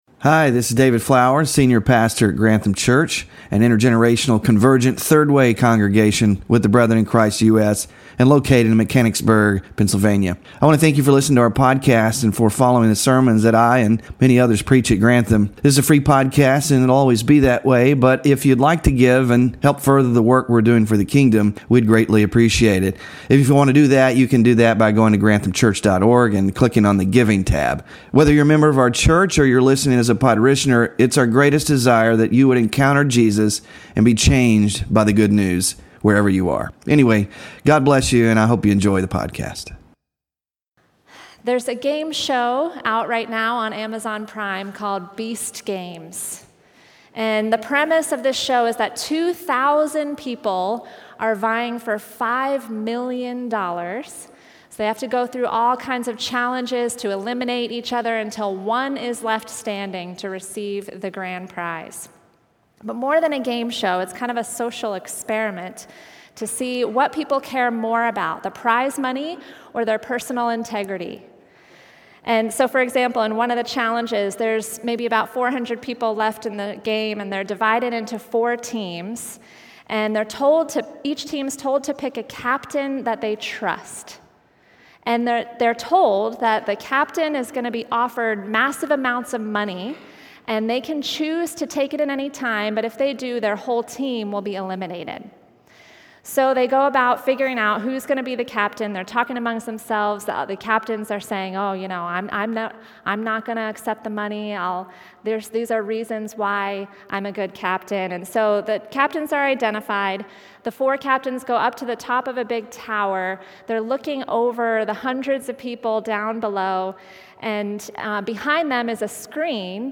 WORSHIP RESOURCES CHILDREN OF FREEDOM SERMON SLIDES (4 of 6) SMALL GROUP QUESTIONS (3-30-25) BULLETIN (3-30-25)